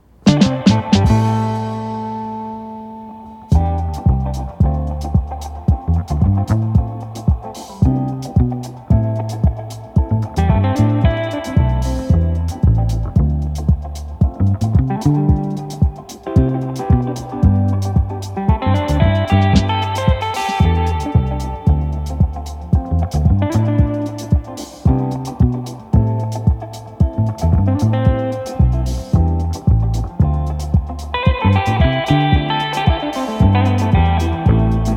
Нестандартные аккорды и неожиданные переходы трека
2024-03-21 Жанр: Альтернатива Длительность